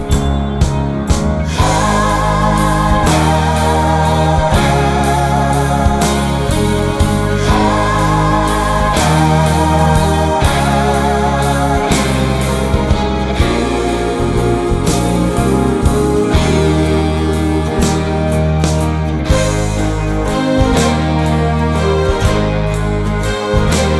Jazz / Swing